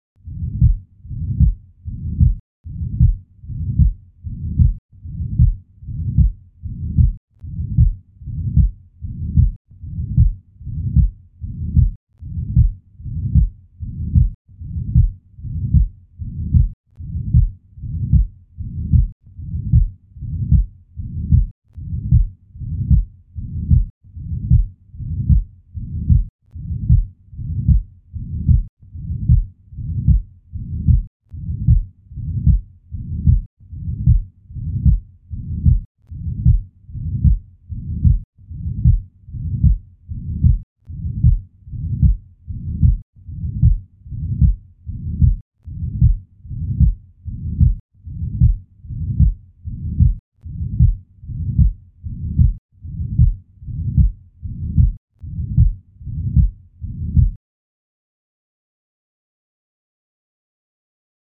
Ventricular Septal Defect-Left